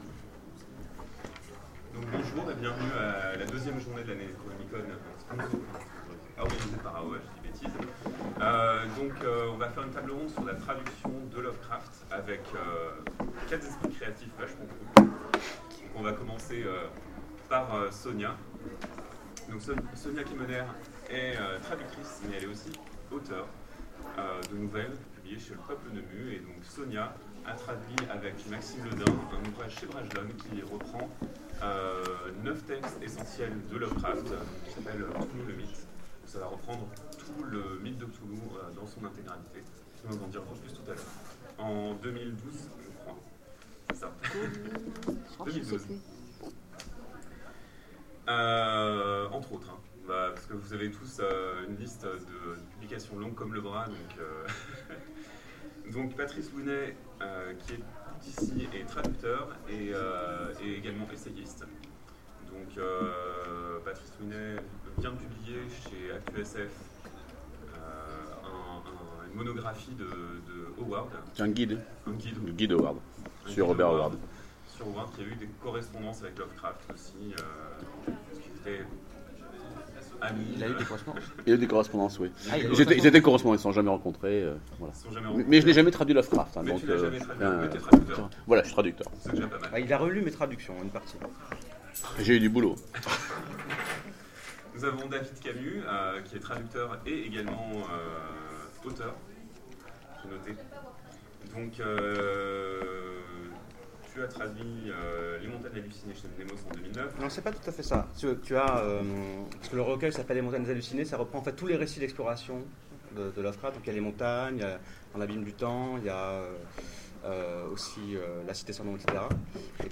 Necronomicon 2015 : Conférence Traduire HP Lovecraft